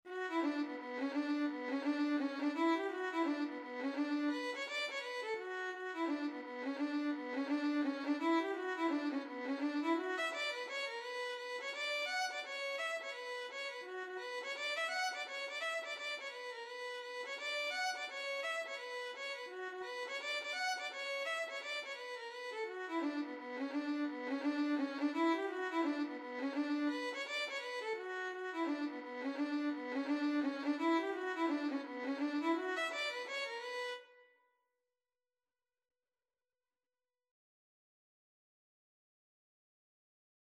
B minor (Sounding Pitch) (View more B minor Music for Violin )
Violin  (View more Intermediate Violin Music)
Traditional (View more Traditional Violin Music)
Reels
Irish